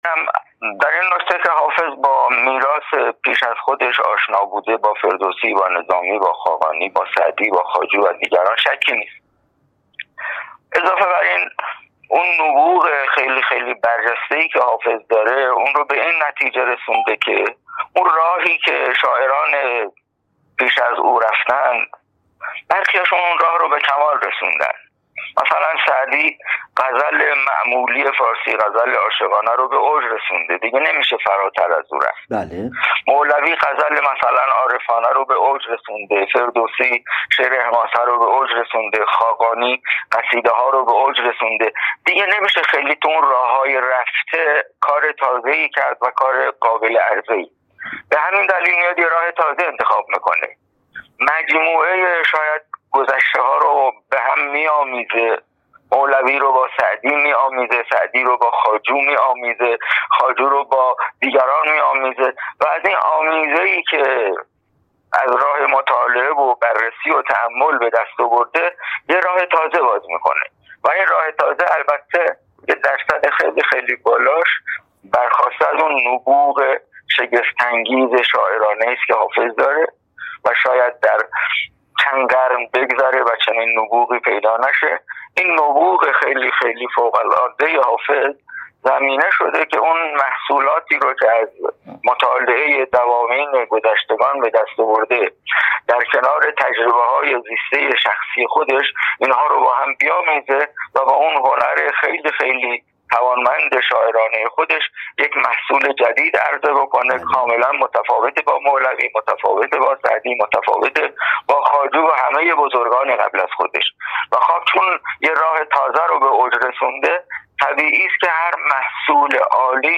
بربلندای امواج تلفن